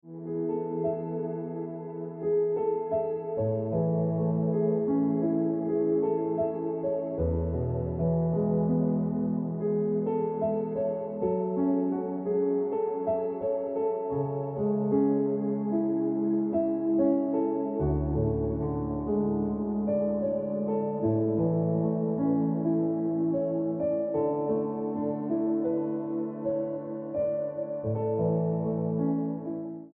Best Ringtones, Piano Music Ringtone